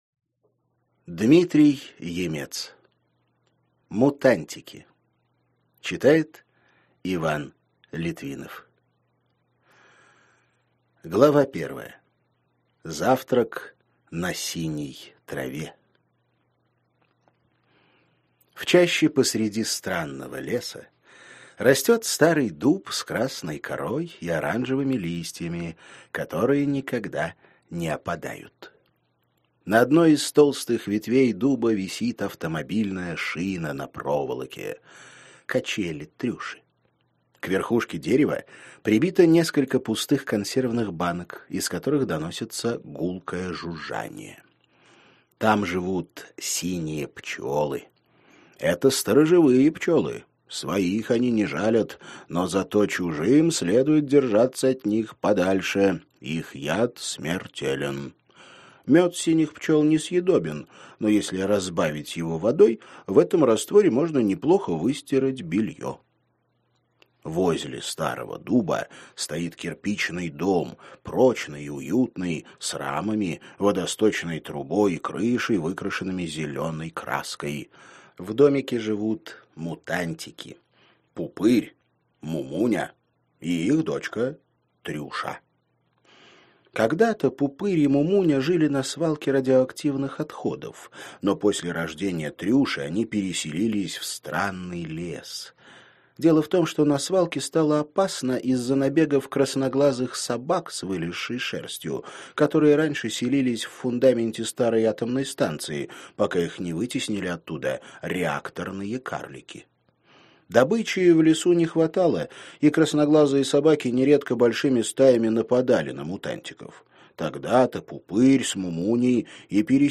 Аудиокнига Мутантики | Библиотека аудиокниг
Прослушать и бесплатно скачать фрагмент аудиокниги